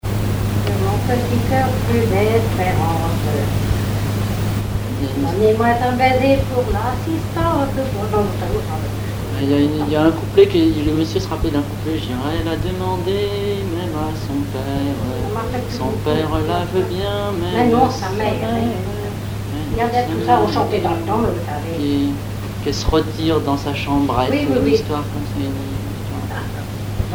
Genre strophique
Chansons et commentaires
Pièce musicale inédite